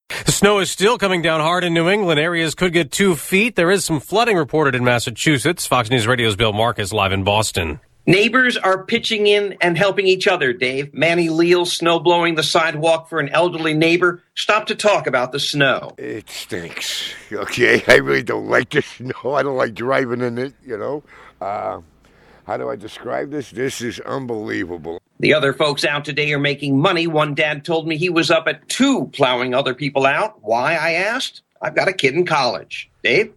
LIVE AT 10AM